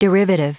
Prononciation du mot : derivative
derivative.wav